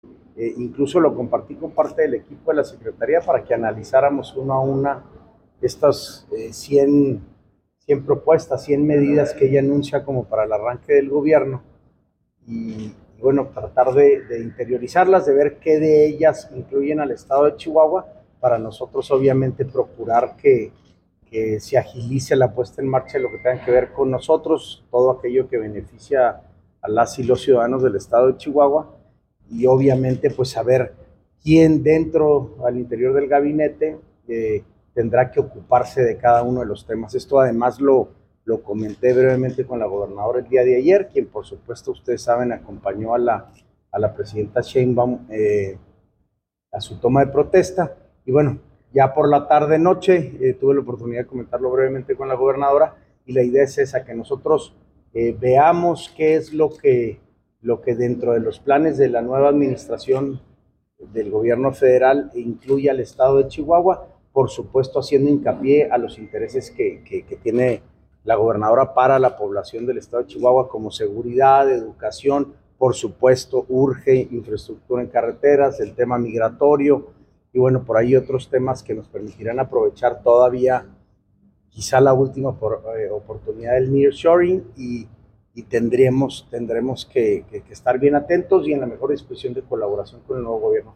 AUDIO: SANTIAGO DE LA PEÑA, SECRETARIO GENERAL DE GOBIERNO (SGG)